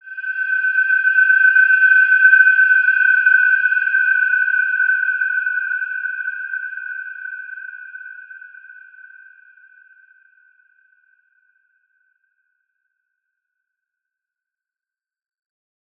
Wide-Dimension-G5-mf.wav